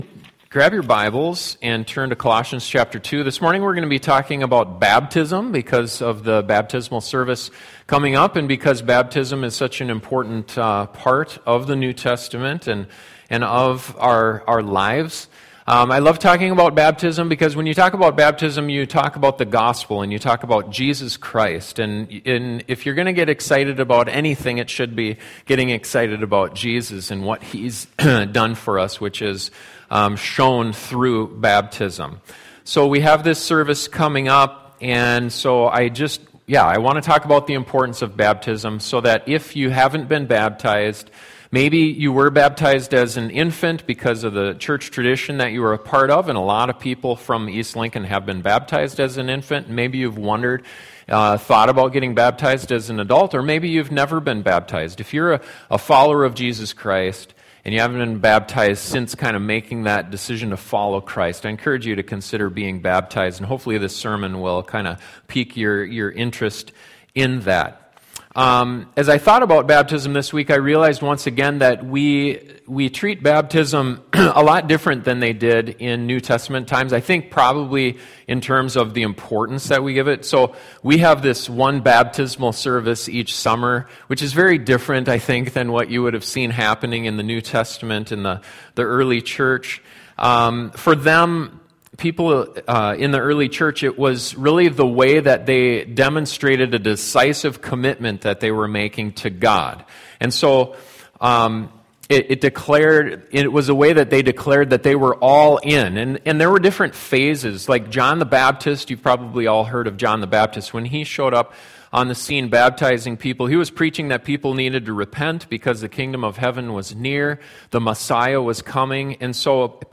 What is the significance of being baptized as a follower of Christ? This sermon looks at the symbolism of baptism and why it’s so important for every Christian to consider.